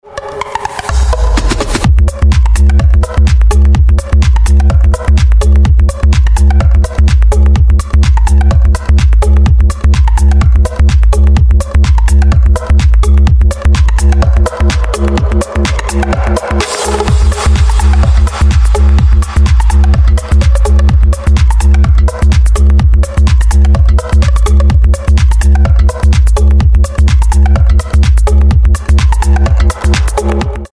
• Качество: 320, Stereo
заводные
dance
спокойные
без слов
качающие
Tech House
Bass
electro
Качающий танцевальный рингтон